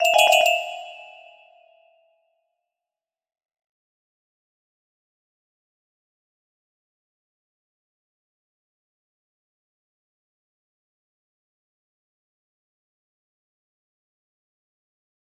Unknown Artis music box melody